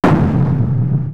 explosions.wav